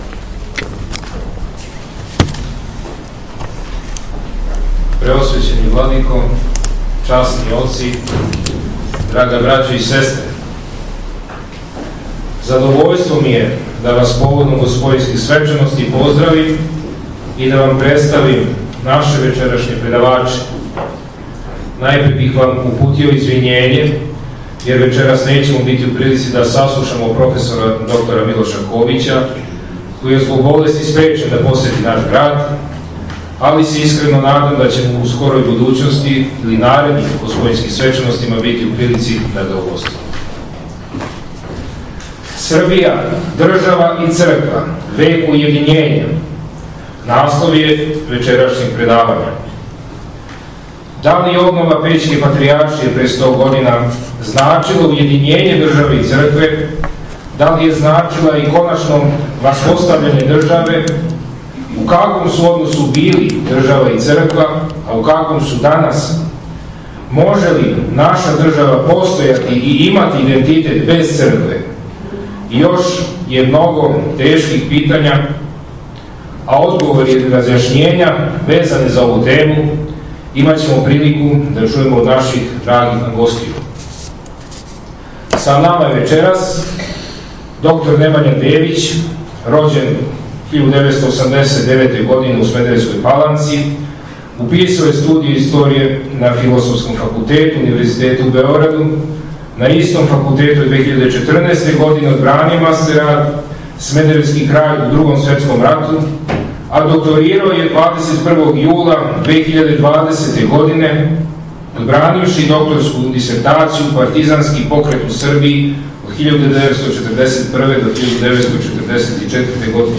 У недељу, 13. септембра 2020. године, у свечаној сали Саборног храма одржано је треће и закључно предавање у оквиру манифестације једанаестих Великого...